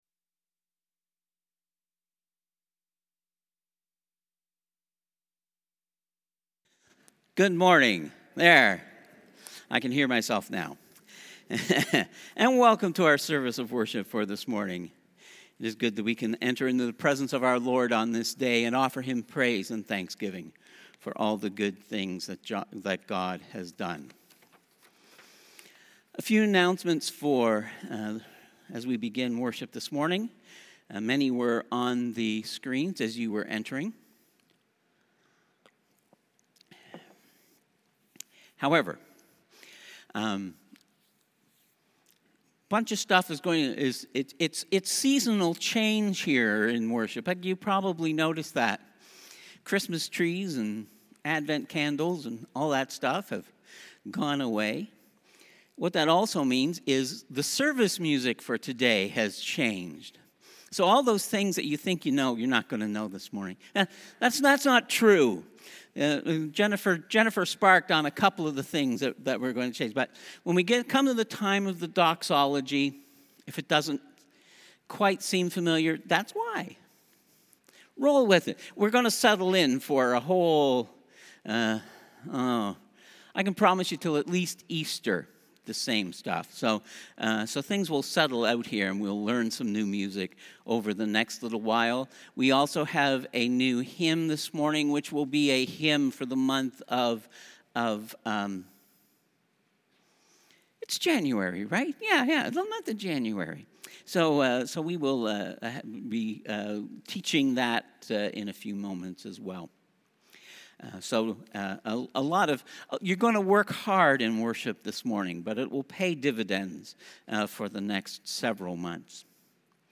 Passage: Luke 3: 15-17 Service Type: Holy Day Service Scriptures and sermon from St. John’s Presbyterian Church on Sunday